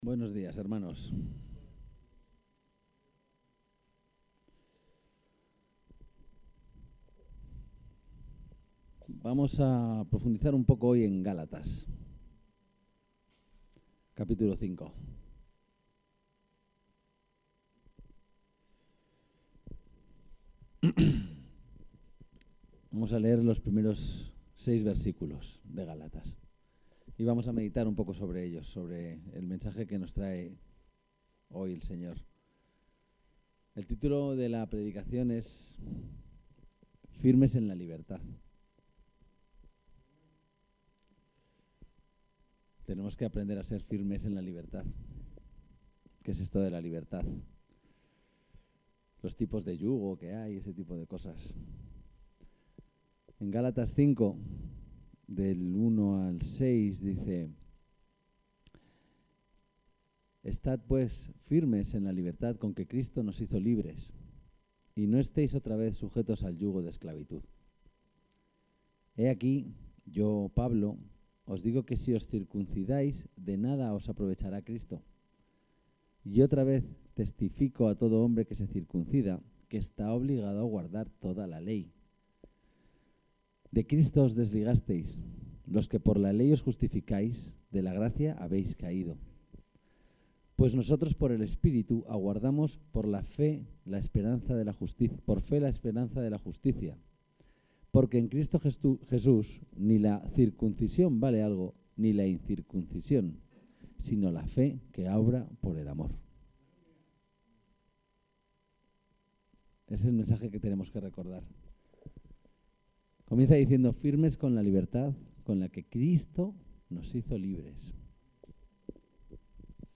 El texto de la predicación también está disponible aquí: Firmes en la libertad